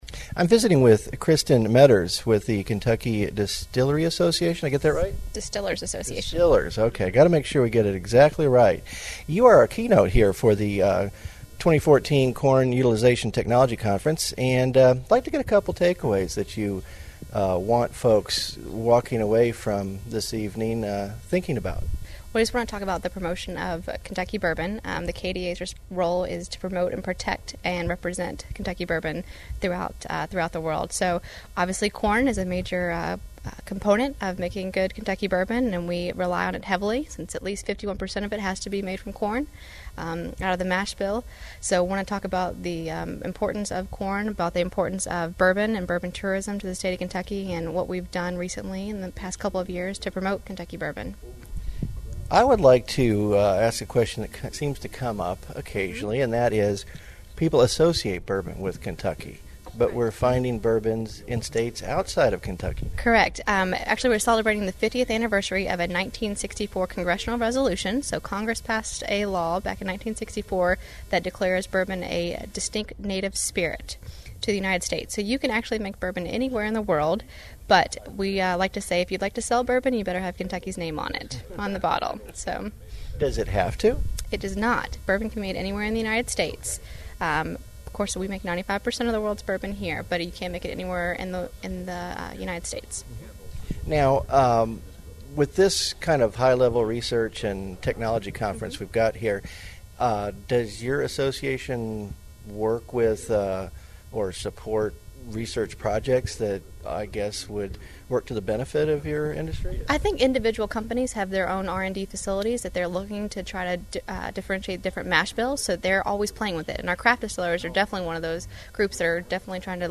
cutclogoThe National Corn Growers Association 2014 Corn Utilization and Technology Conference was held June 2-4 in Louisville, Kentucky.
Interview